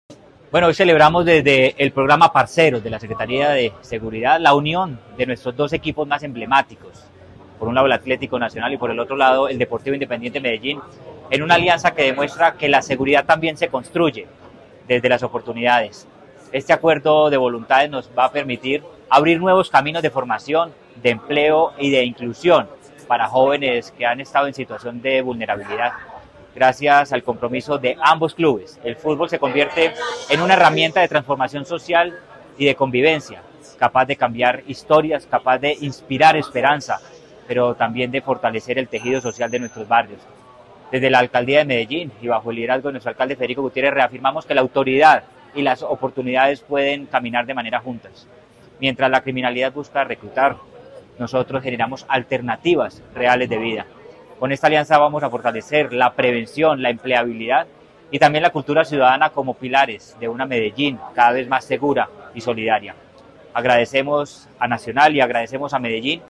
Declaraciones-secretario-de-Seguridad-y-Convivencia-de-Medellin-Manuel-Villa-Mejia.mp3